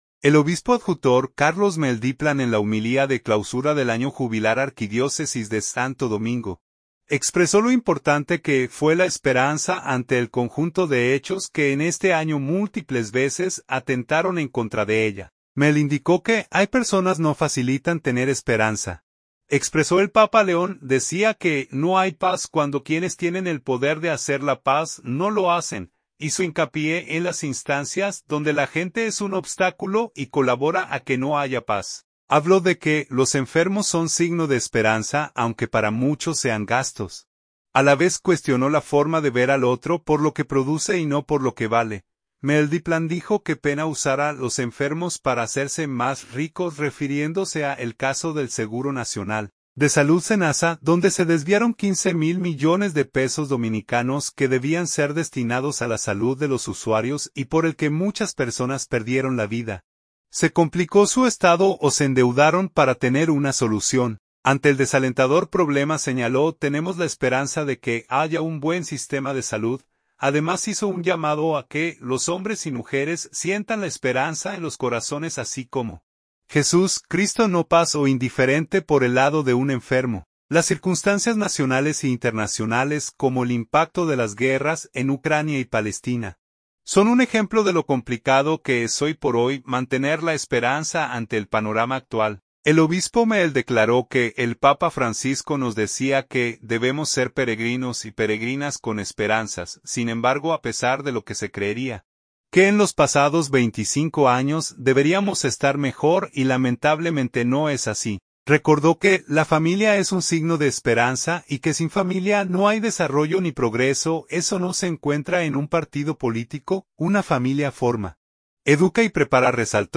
El obispo adjutor, Carlos Morel Diplan en la homilía de Clausura del año Jubilar Arquidiócesis de Santo Domingo, expresó lo importante que fue la esperanza ante el conjunto de hechos que en este año múltiples veces atentaron en contra de ella.